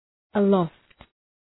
Shkrimi fonetik {ə’lɒft}